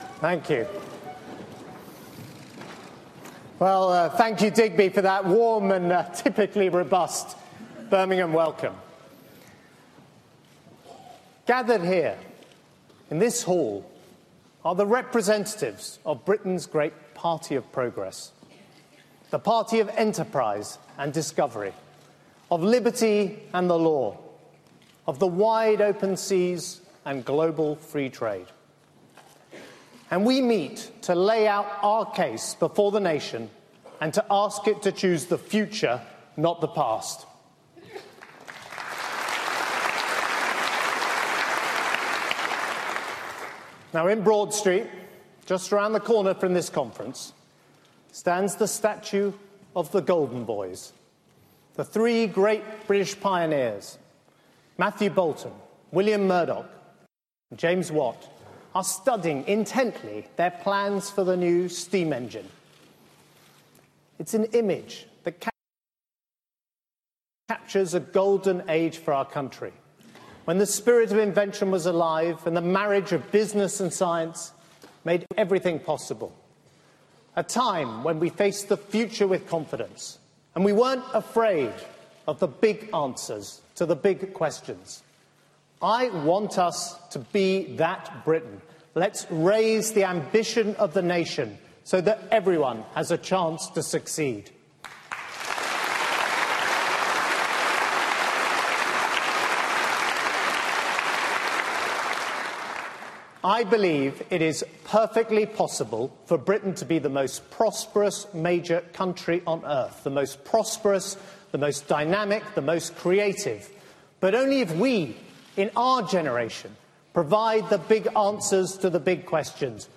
George Osborne's speech to the Tory conference